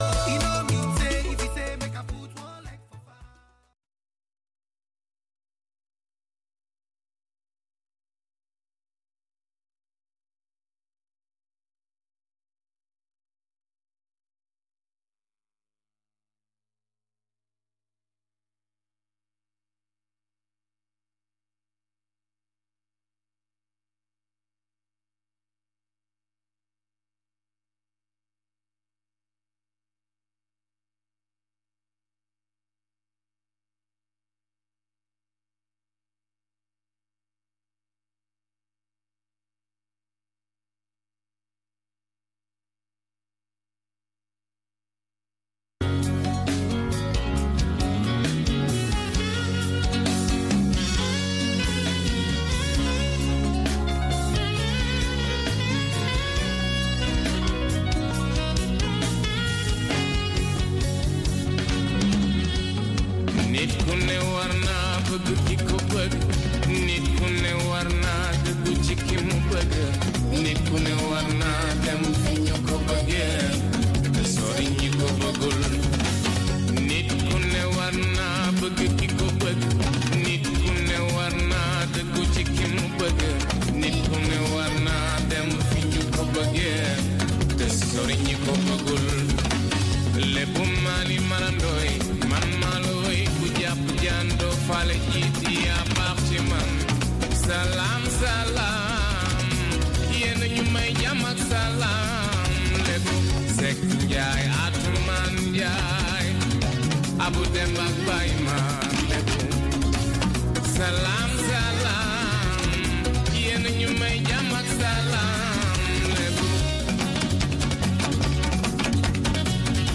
Zouk, Reggae, Latino, Soca, Compas et Afro
interviews de divers artistes